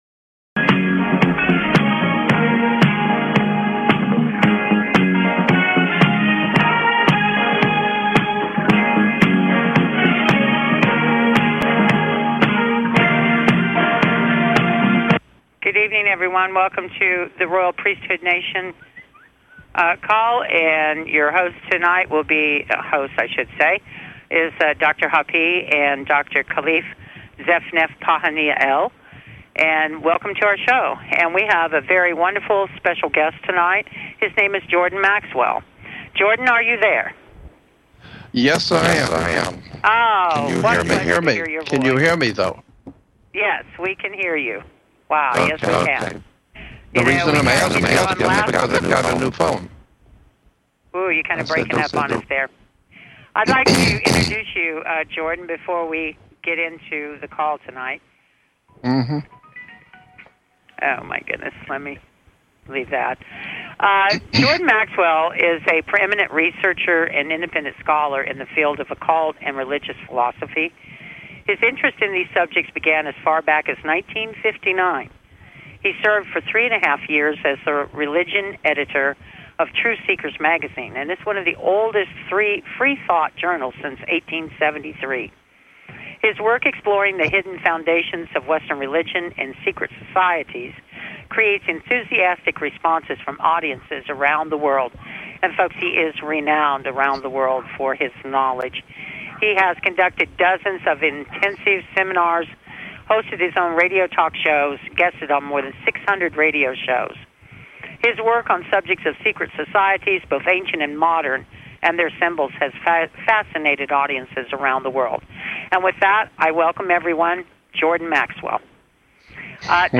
Guest, Jordan Maxwell